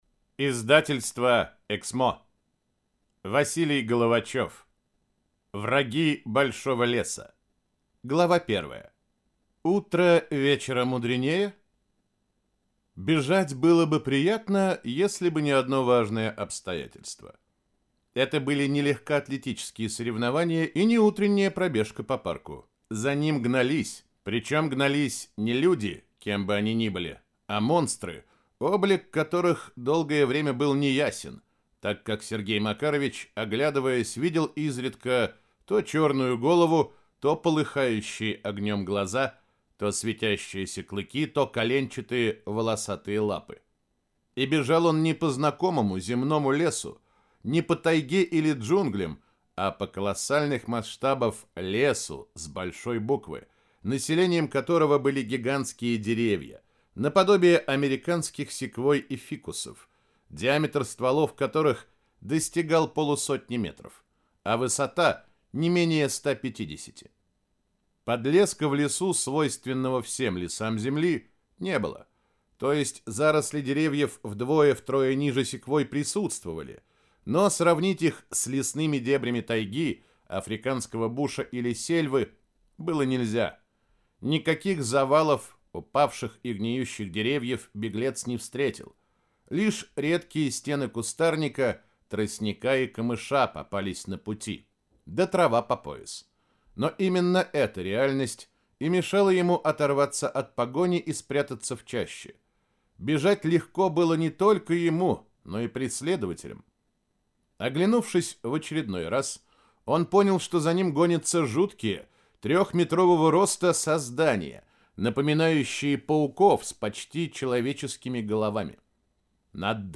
Аудиокнига Враги большого леса | Библиотека аудиокниг